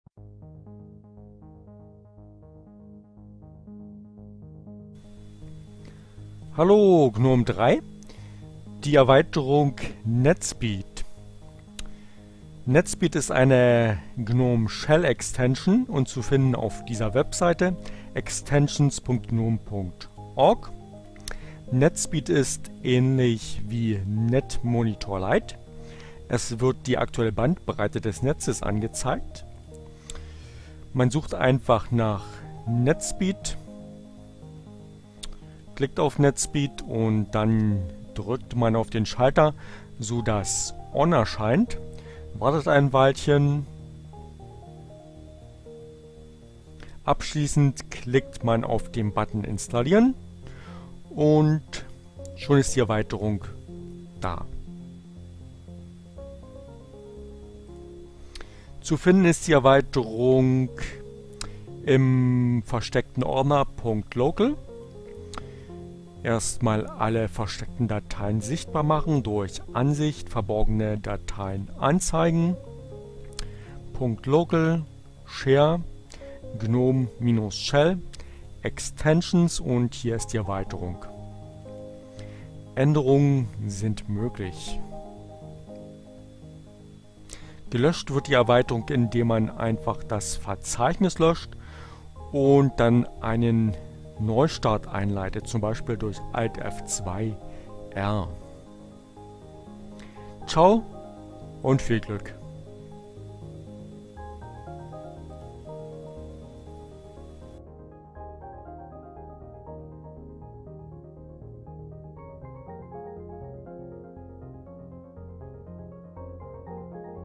ohne Musik , screencast